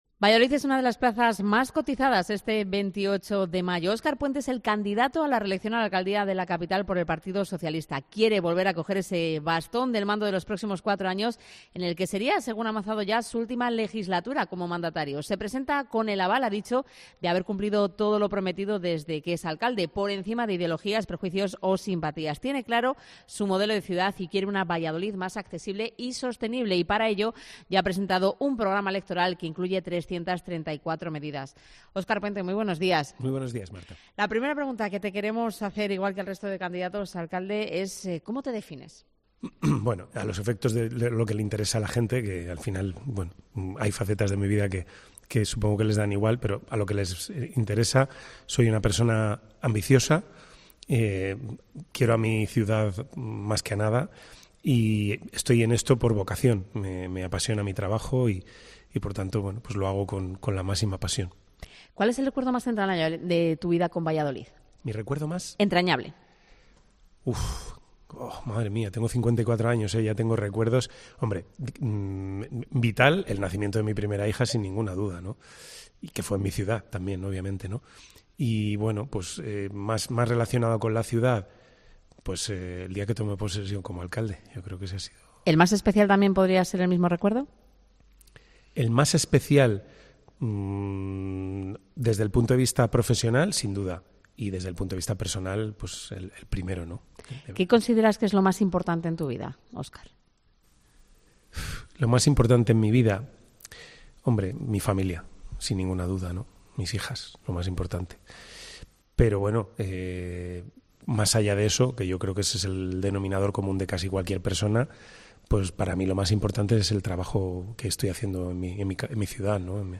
Entrevista Óscar Puente. Alcalde de Valladolid y candidato a la reelección